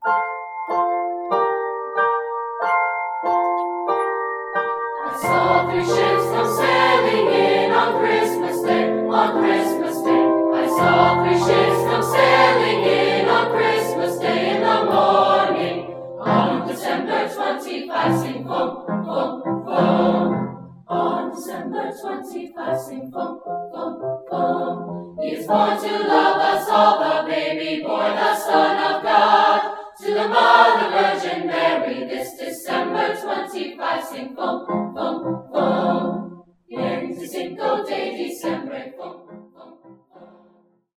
Swift Creations specializes in live and on location recordings in Pennsylvania.
Choir 1
choir1.mp3